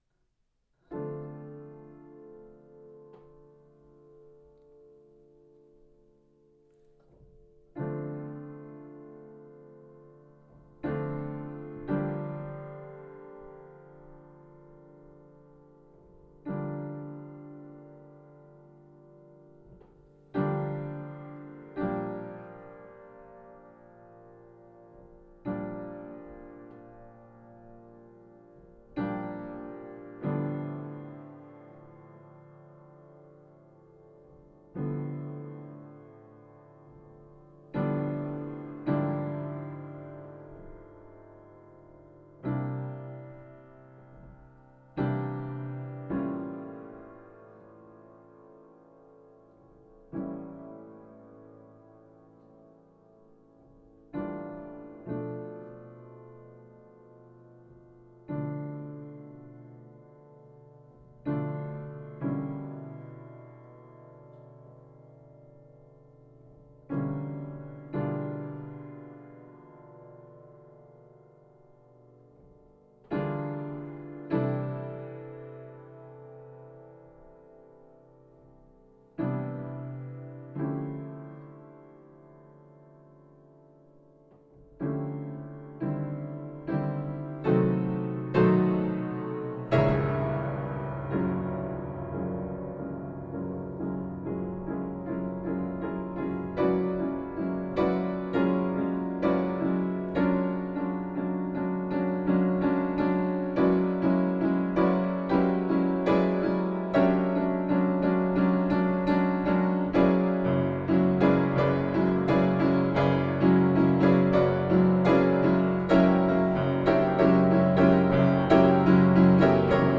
piano improvisation 26.04.2020